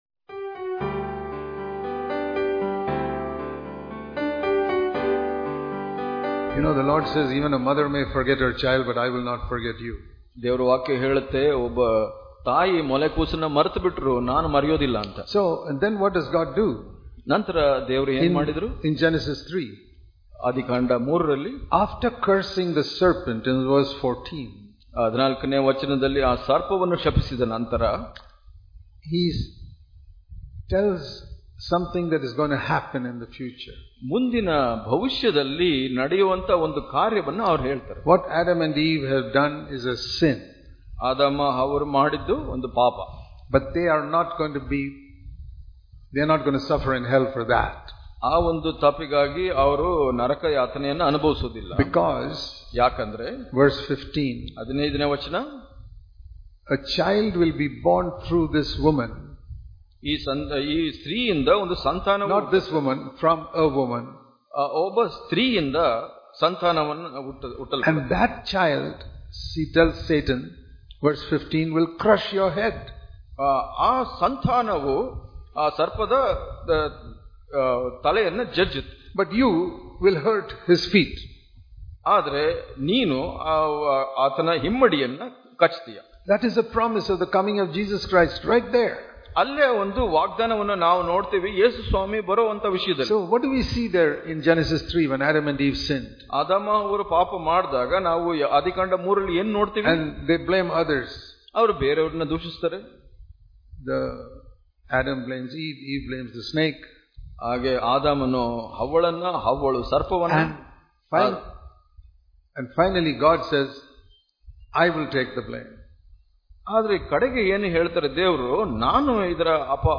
June 23 | Kannada Daily Devotion | Understanding God's Love Daily Devotions